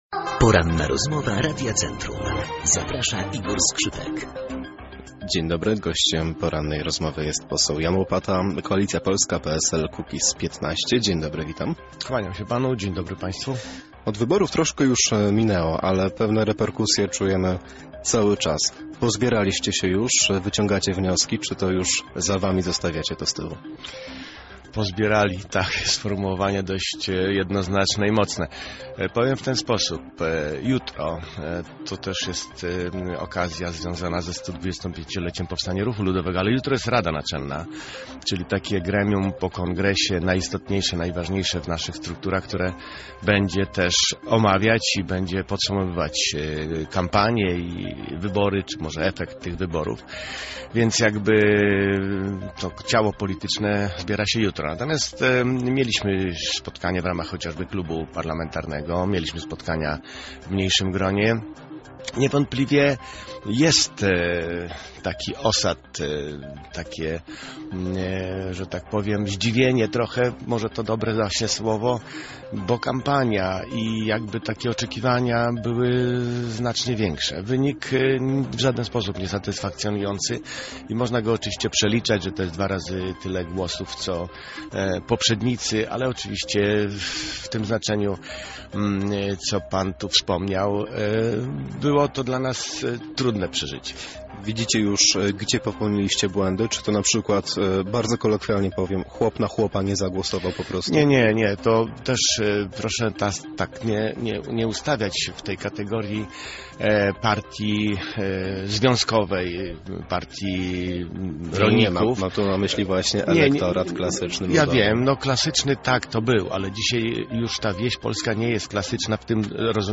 Poranna-Rozmowa-Radia-Centrum-Jan-Łopata.mp3